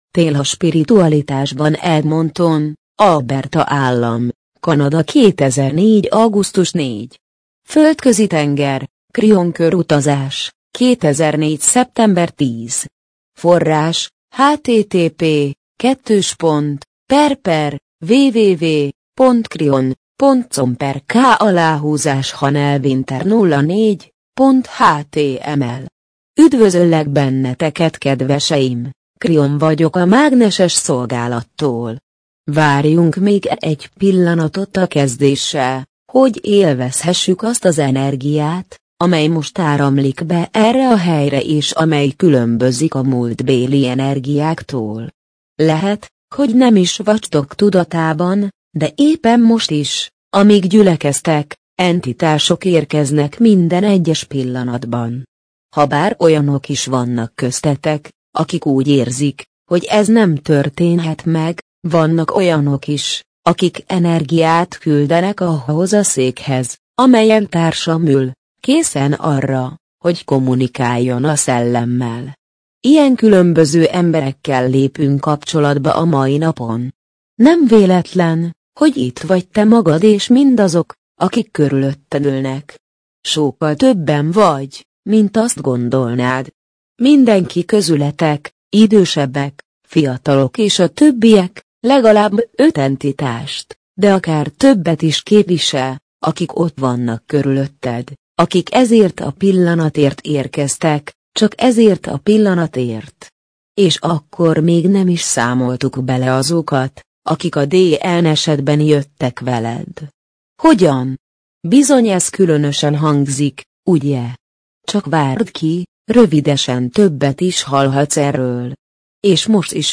MP3 gépi felolvasás Tél a spiritualitásban Tél a spiritualitásban Edmonton, Alberta állam, Kanada - 2004. augusztus 4.